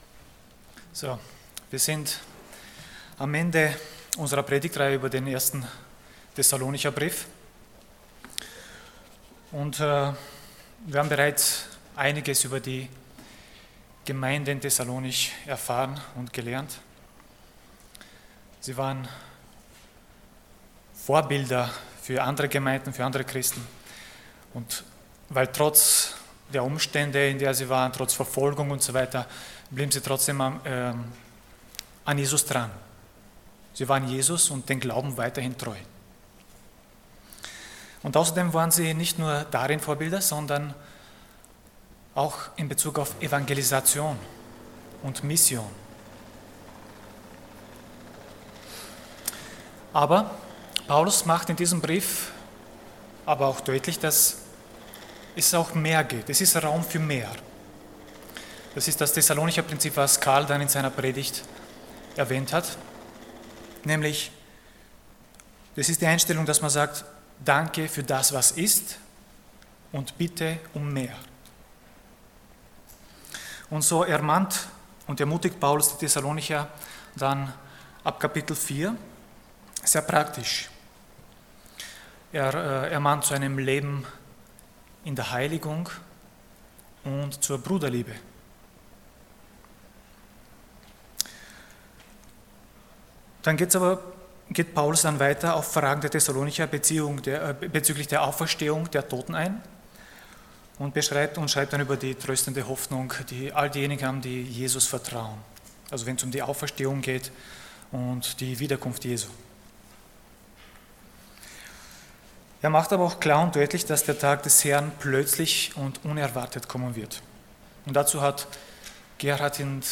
Passage: 1 Thessalonians 5:12-24 Dienstart: Sonntag Morgen